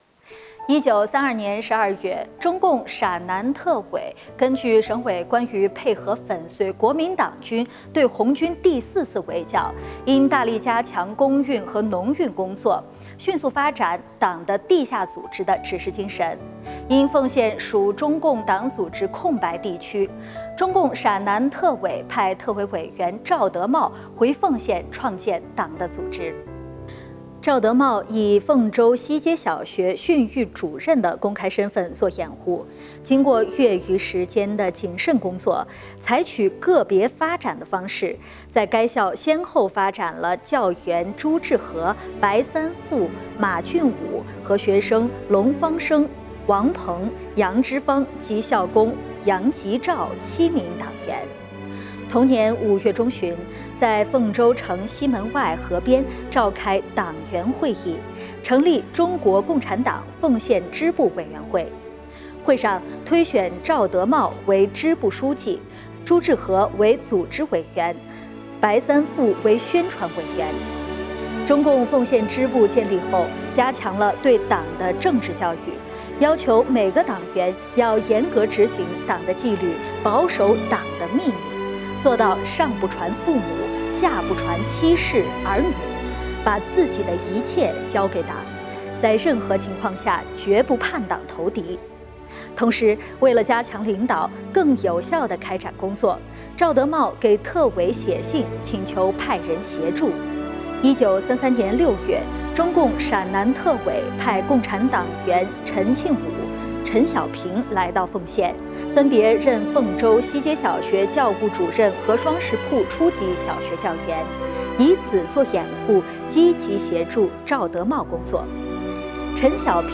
【红色档案诵读展播】第一个中共凤县支部的建立